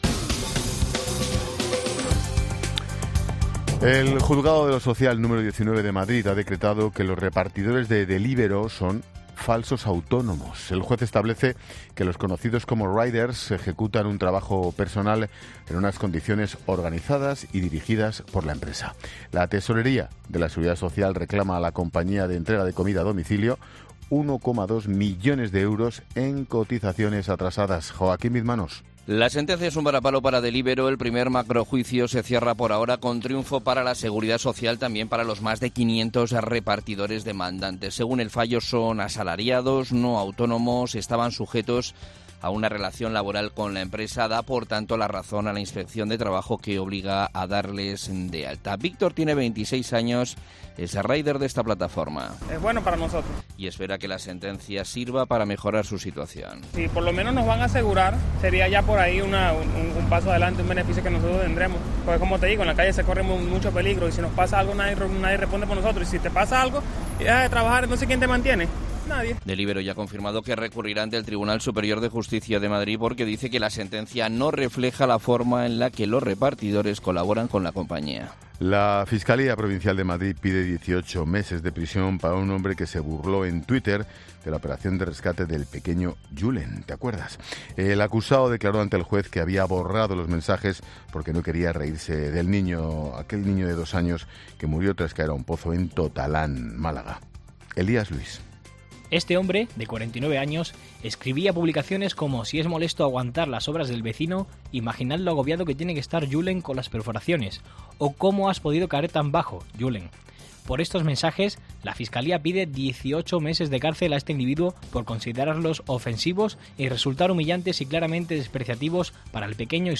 Boletín de noticias de COPE del 23 de julio de 2019 a las 21.00 horas